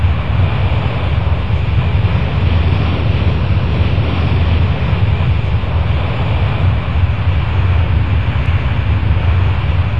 cfm-rumbleDist.wav